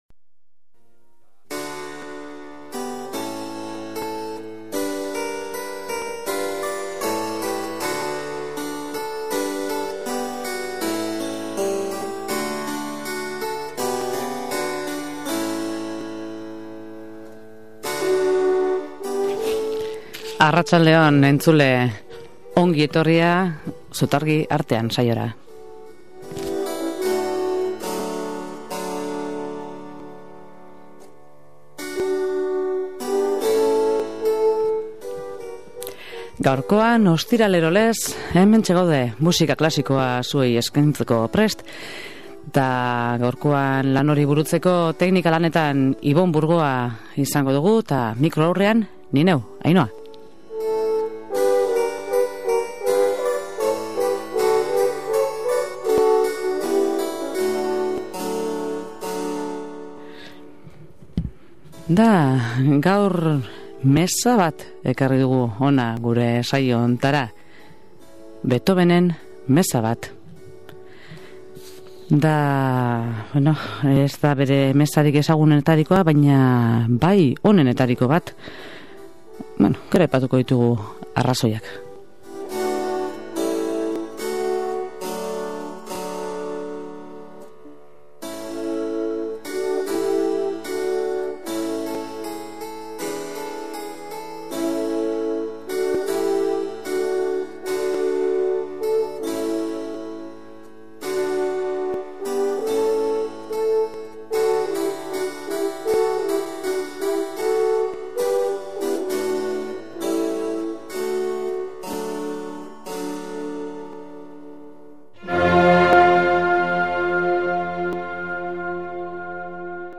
Gaurko saioan Beethoven-en meza bat entzuteko aukera izango dugu. Do Majorrean idatzitakoa, Op.86.